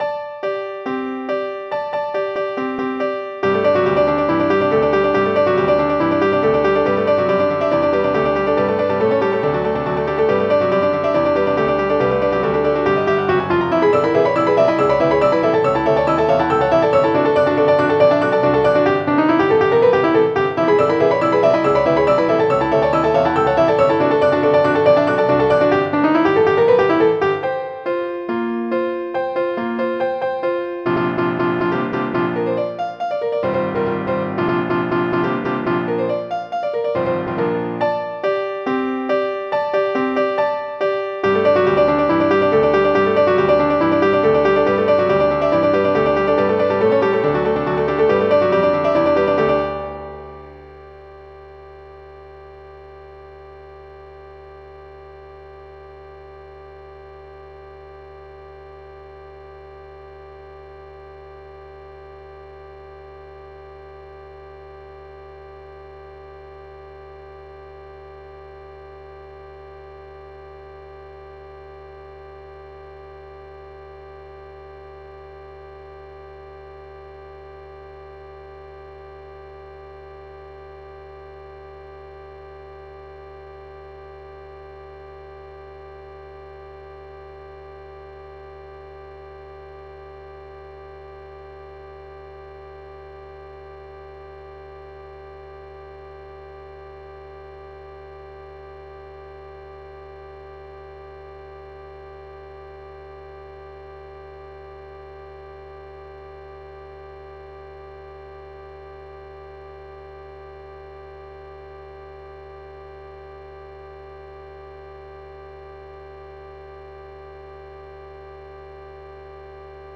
MIDI Music File
hoedown.mp3